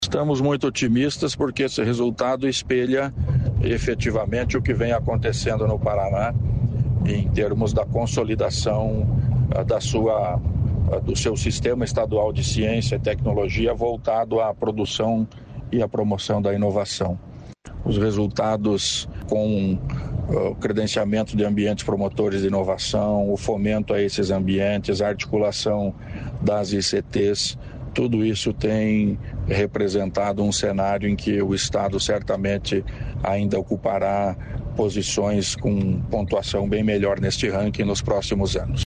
Sonora do secretário estadual da Ciência, Tecnologia e Ensino Superior, Aldo Bona, sobre o salto da inovação na última década